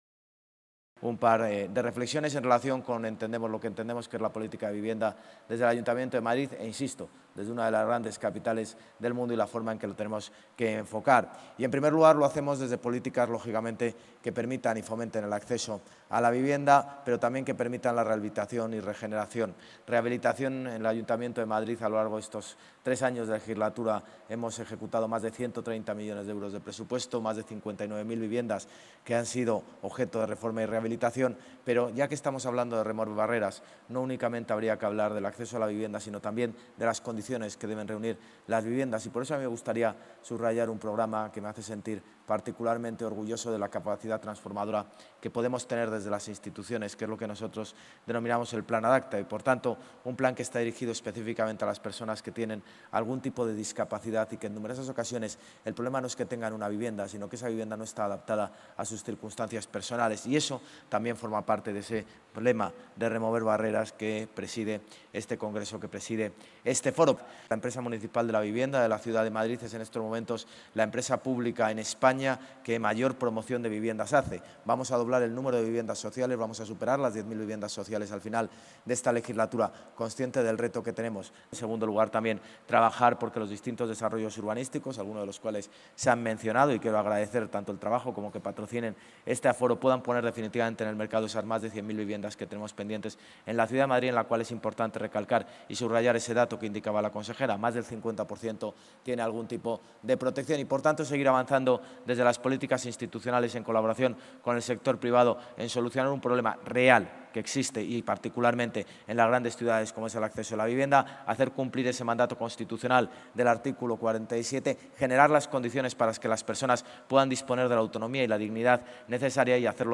El alcalde ha participado en la apertura del foro mundial de vivienda organizado por la Unión Internacional de Arquitectos y el Ayuntamiento de Madrid
Nueva ventana:José Luis Martínez-Almeida, alcalde de Madrid